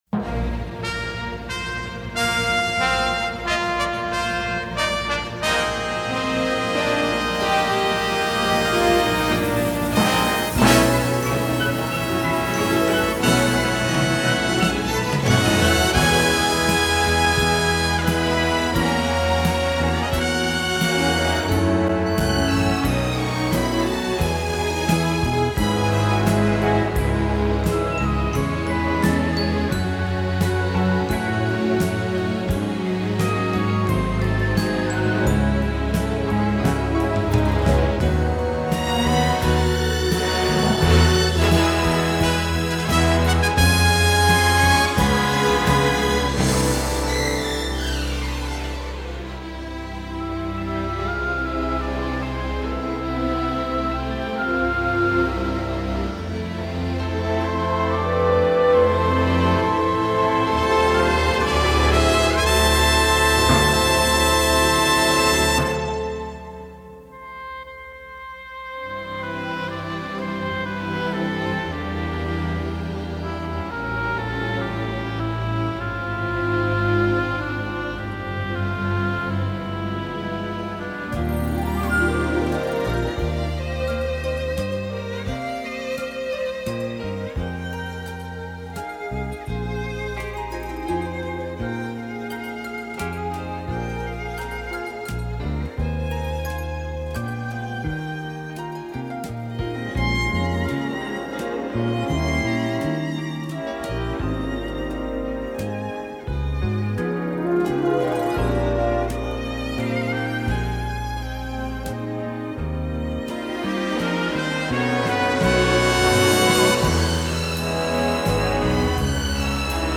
cantata de Natal
(Instrumental) - (Coro)